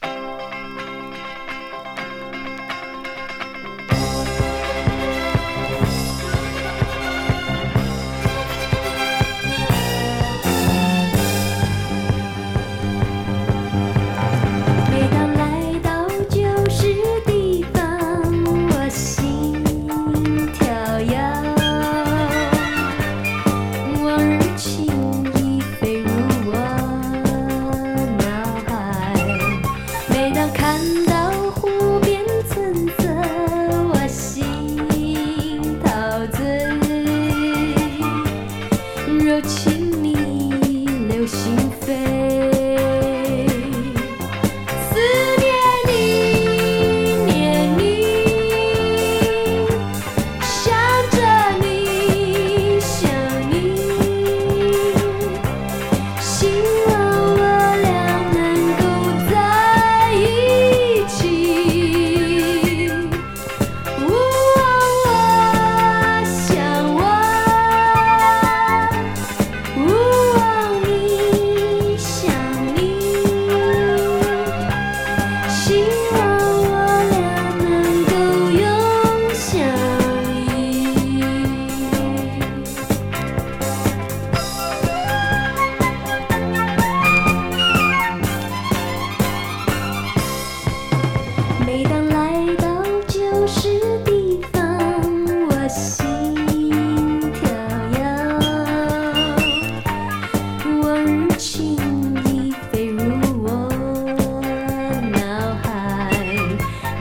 ライトなディスコ